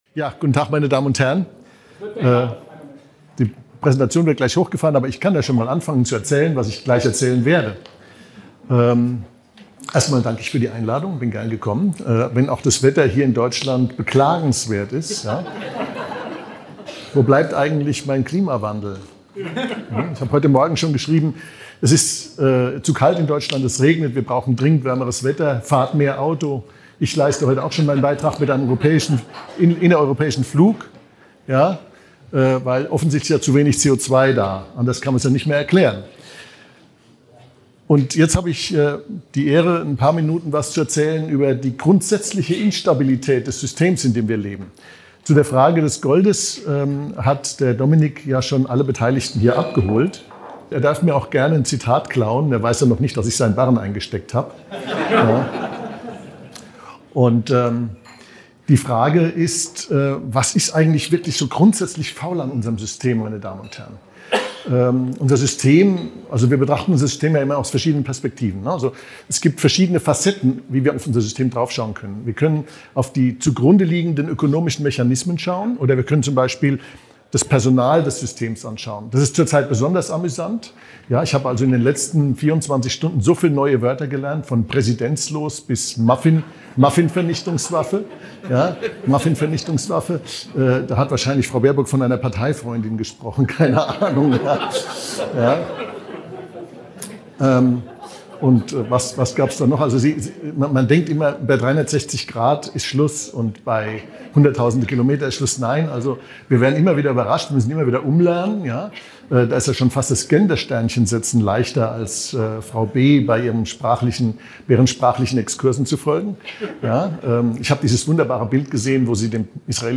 Beschreibung vor 1 Jahr In diesem Vortrag auf dem Kapitaltag am 19. April 2024 in Hannover analysiert Dr. Markus Krall kritisch das aktuelle Finanzsystem. Er erläutert den Übergang vom Gold- zum Fiat-Geld, warnt vor einer drohenden Hyperinflation und hinterfragt die Zukunft des US-Dollars als Leitwährung. Krall diskutiert die Bestrebungen der BRICS-Staaten für eine goldgedeckte Alternative und prognostiziert eine mögliche Rückkehr zum Goldstandard.